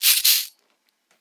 • Shaker Percussion C Key 37.wav
Royality free shaker percussion tuned to the C note. Loudest frequency: 8284Hz
shaker-percussion-c-key-37-pLm.wav